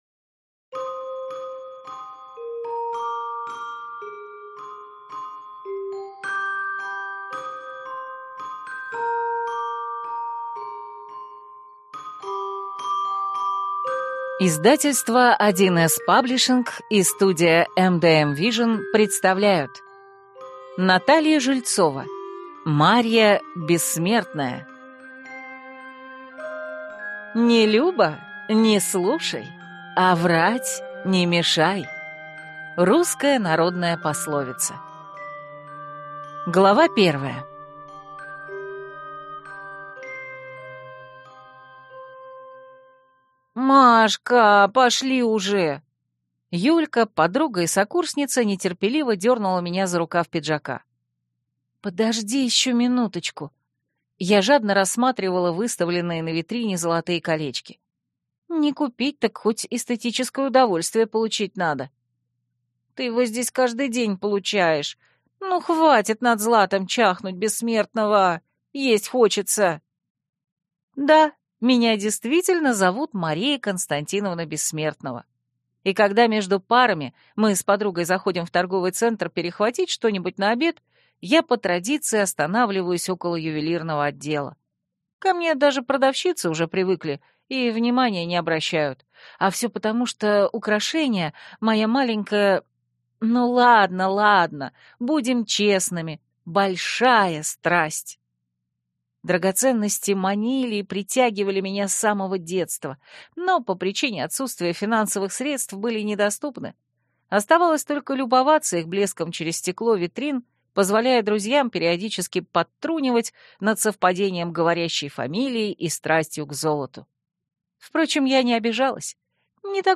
Аудиокнига Марья Бессмертная - купить, скачать и слушать онлайн | КнигоПоиск
Аудиокнига «Марья Бессмертная» в интернет-магазине КнигоПоиск ✅ в аудиоформате ✅ Скачать Марья Бессмертная в mp3 или слушать онлайн